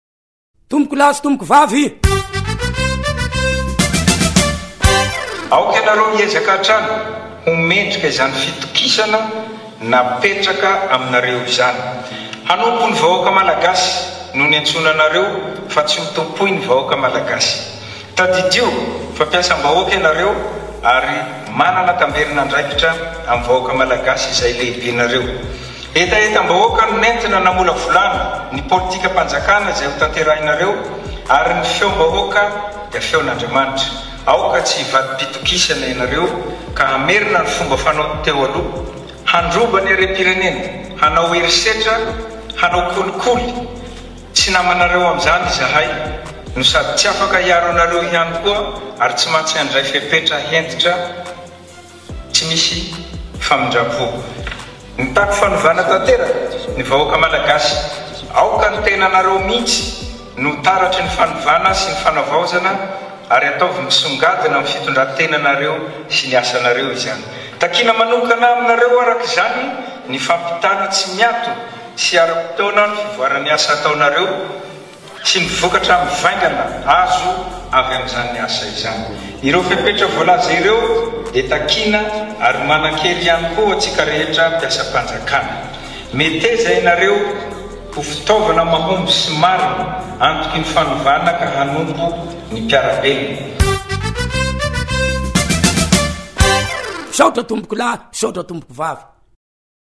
Akon'i Gasikara - Kabary nataon’i Michaël Randrianirina – Toromarika ho an’ny governemanta vaovao
Kabary manan-danja nataon’i Michaël Randrianirina ho an’ny minisitra vaovao, mampahatsiahy fa ny vahoaka no tompon’andraikitra amin’izy ireo.